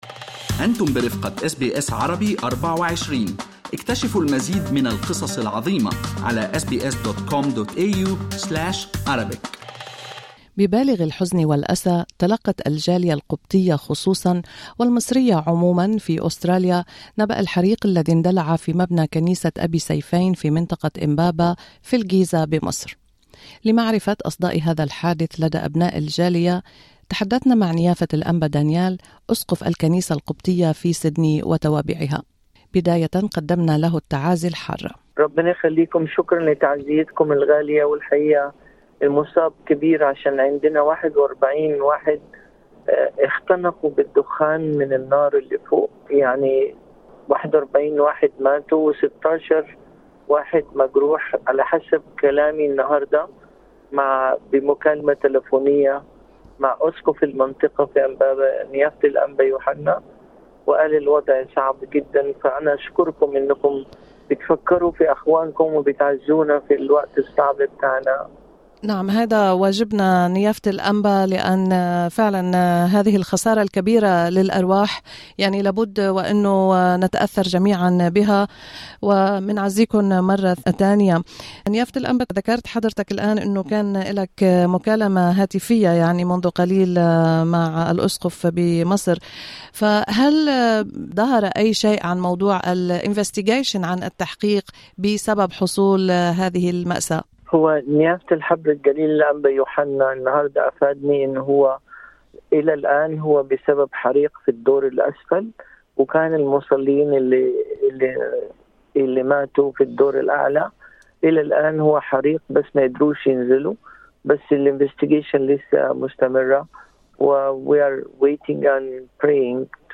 الأنبا دانيال أسقف الكنيسة القبطية لسيدني وتوابعها يعبر عن حزن الجالية ويعد بتقديم مساعدات